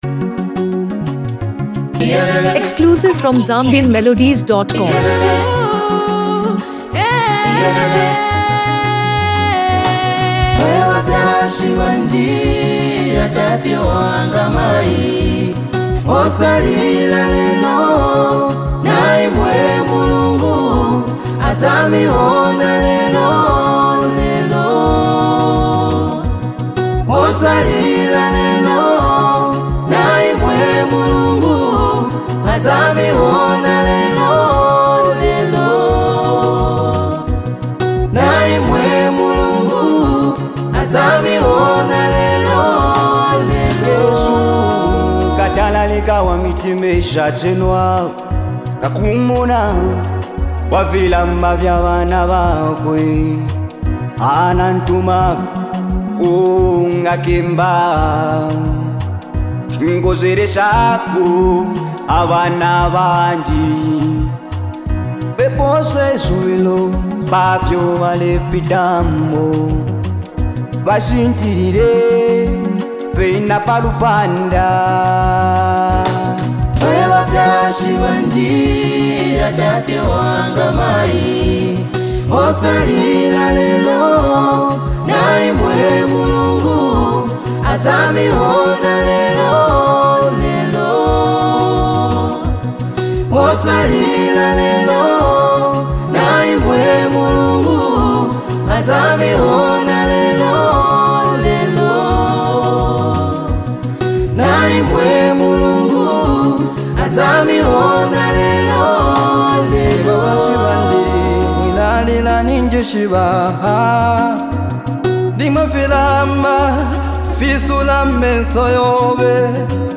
a stirring inspirational single
crisp production accentuates every lyric